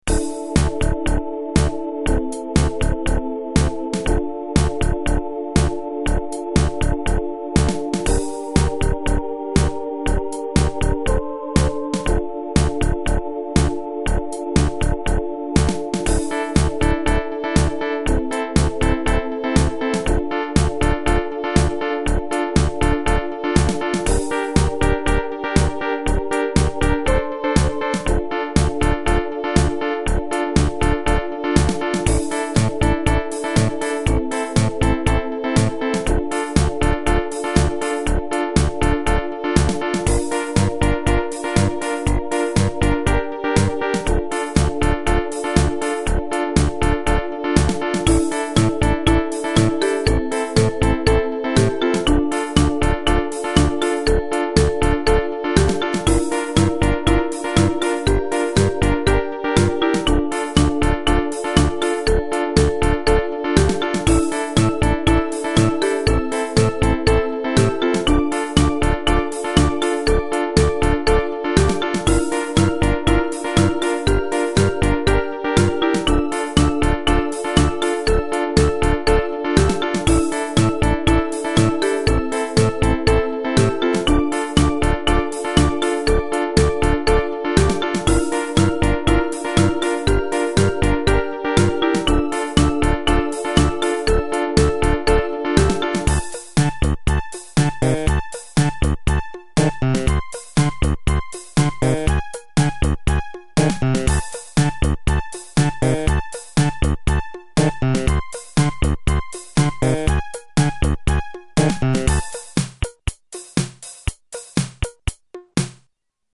• 16 poly
• Music has an ending (Doesn't loop)
Preview[ SE K800 ]